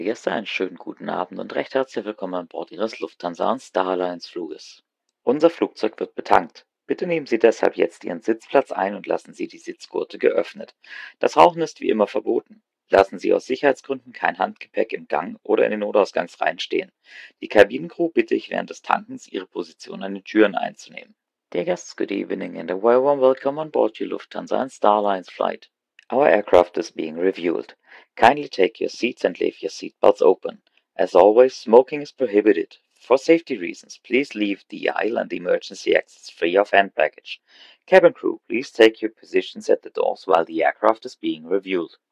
BoardingWelcome[Refueling][Evening].ogg